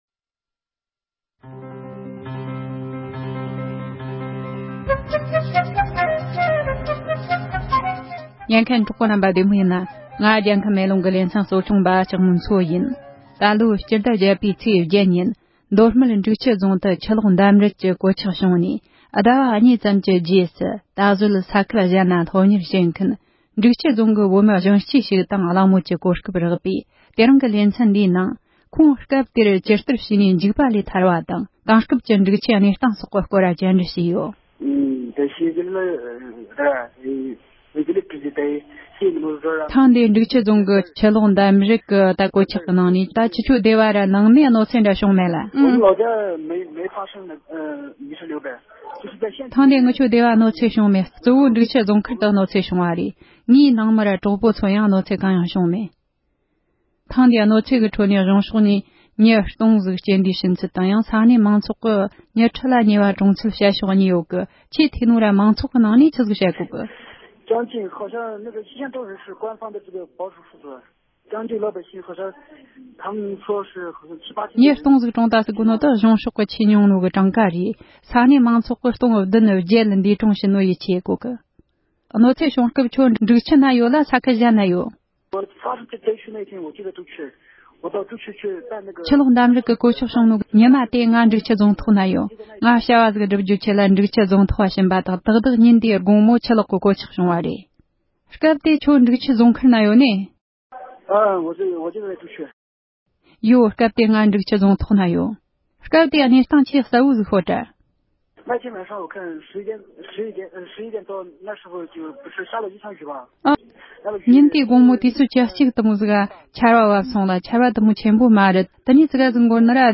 འབྲུག་ཆུ་ས་ཁུལ་གྱི་བོད་མི་གཞོན་སྐྱེས་ཤིག་གིས་ཁུལ་དེར་ཆུ་ལོག་གི་གོད་ཆགས་ཕོག་སྐབས་ཀྱི་གནས་སྟངས་ངོ་སྤྲོད་གནང་བ།
སྒྲ་ལྡན་གསར་འགྱུར།